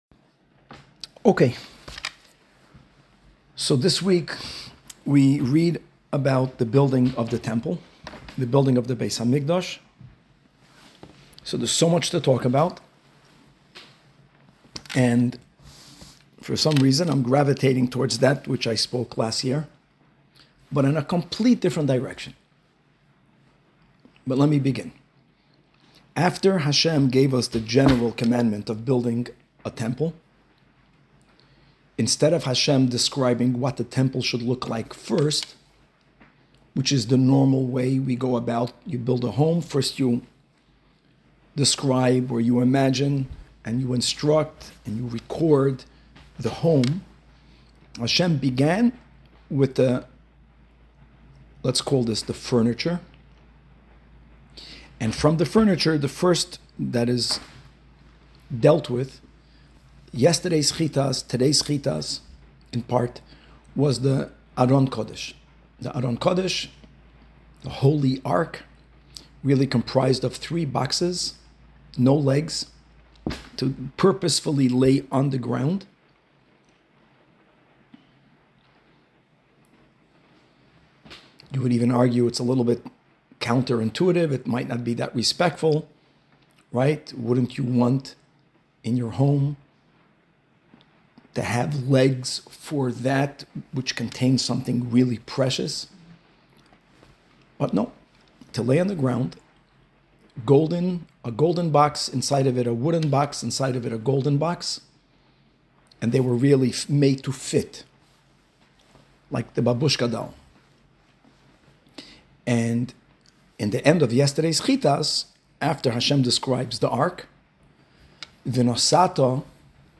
Halacha Shiur from Chabad of SOLA